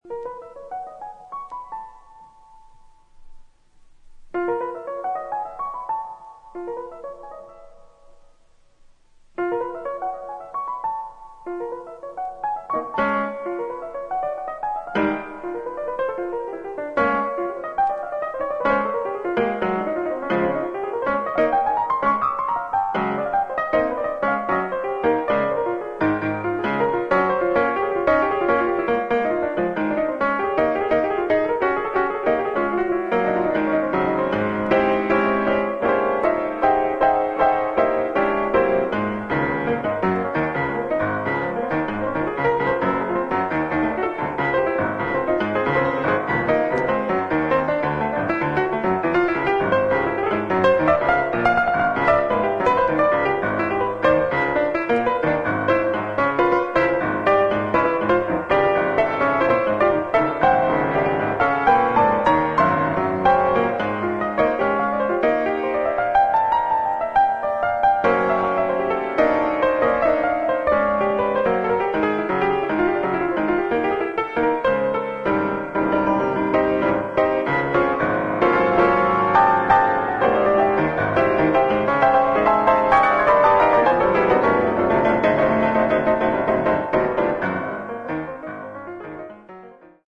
イントロのフレーズが印象的なピアノ独奏曲
ジャズやブルースをベースにしつつも、宗教音楽やアヴァンギャルドな要素が含まれる唯一無二のアルバム。